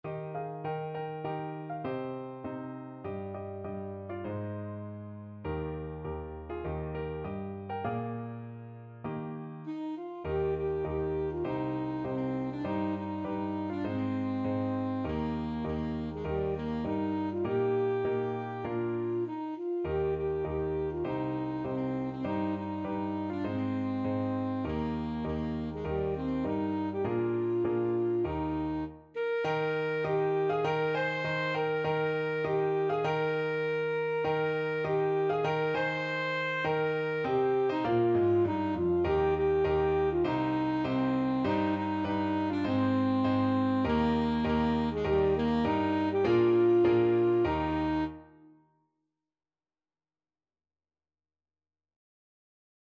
Alto Saxophone
Moderato
4/4 (View more 4/4 Music)